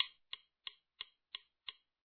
Here’s the metronome beat, which is set at 176 bpm.